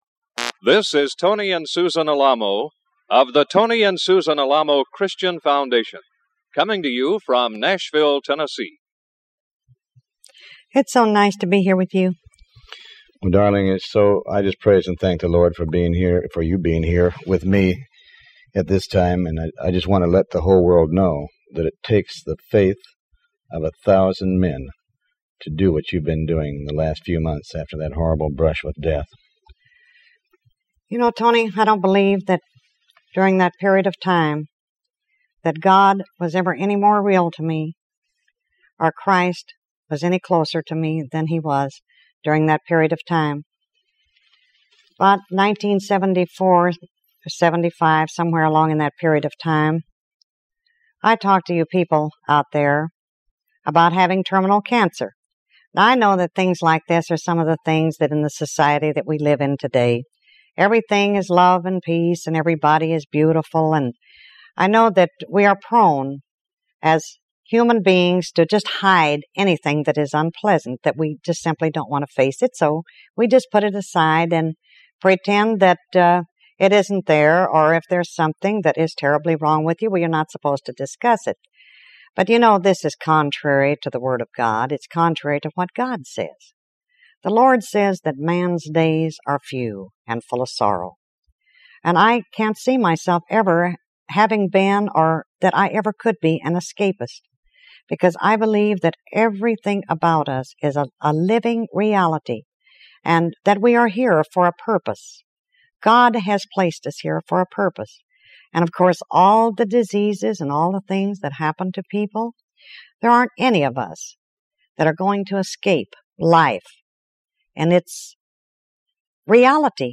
Show Headline Tony Alamo Show Sub Headline Tony And Susan Alamo Program 8 Tony Alamo with Tony Alamo World Wide Ministries Tony And Susan Alamo Program 8 Recorded in Nashville Tennessee in 1976. In this program Susan discusses her ordeal with terminal cancer and her determination to see that she fulfilled her ministry.